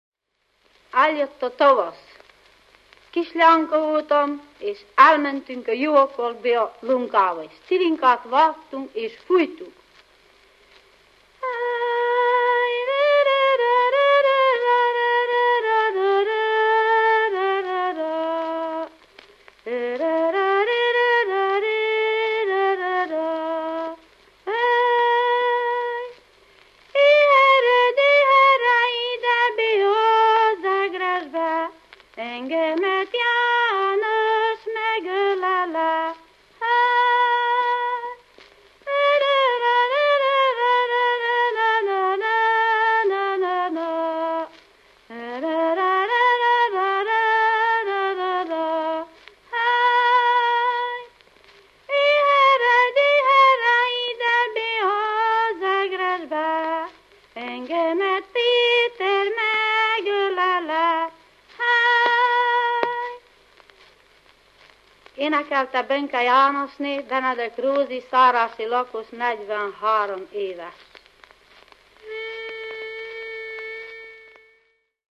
Helység Diószin
Megye Moldva
Cím Pátria, magyar népzenei gramofonfelvételek.